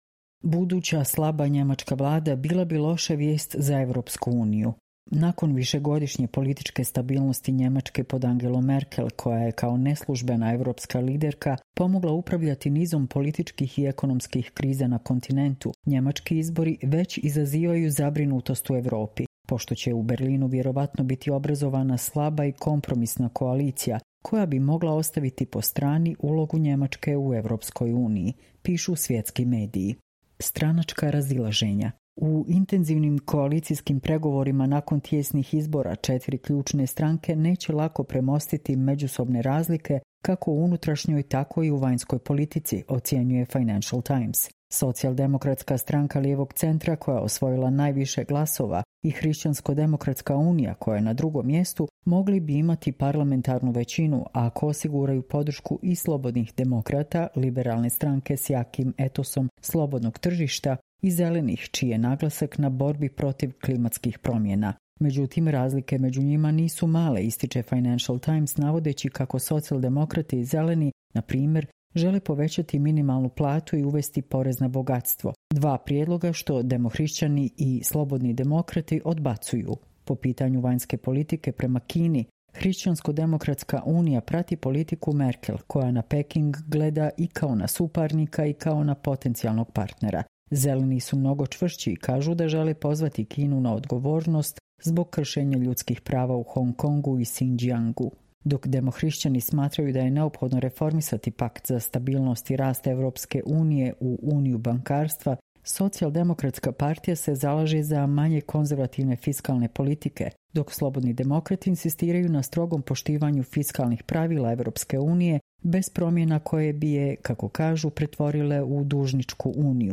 Čitamo vam: Buduća slaba njemačka vlada bila bi loša vijest za Evropsku uniju